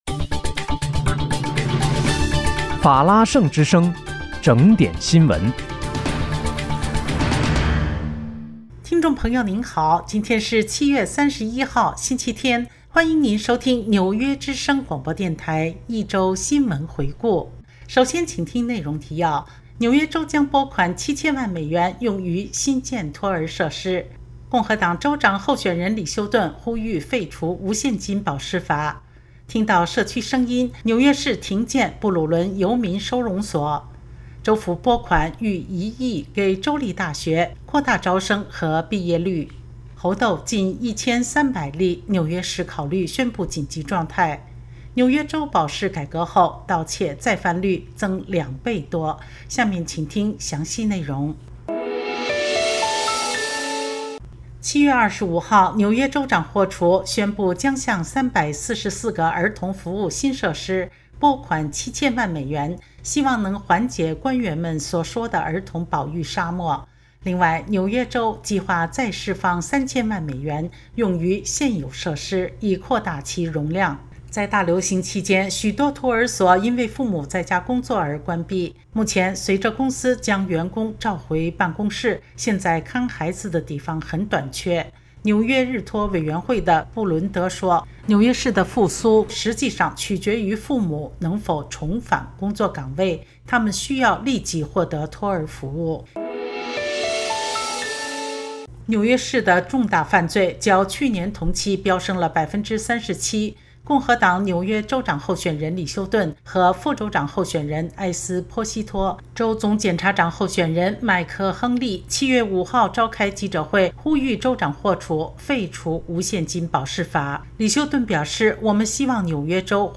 7月31日（星期日）一周新闻回顾